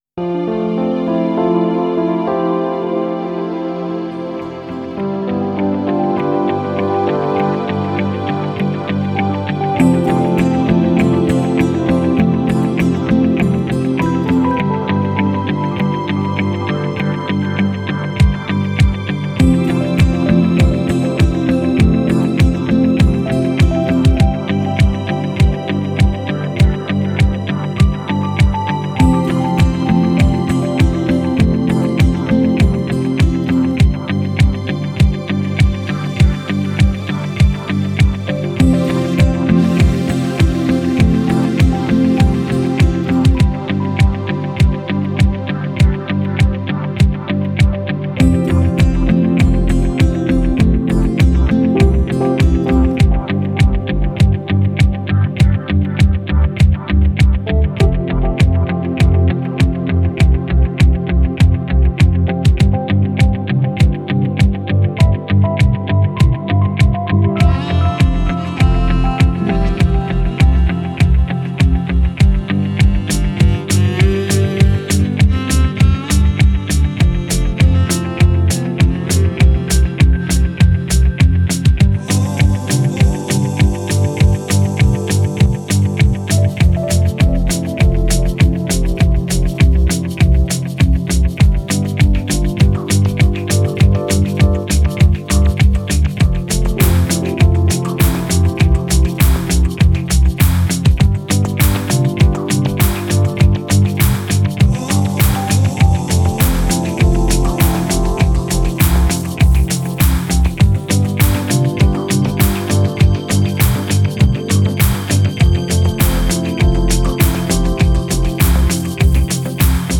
Genre: Downtempo, Chillout.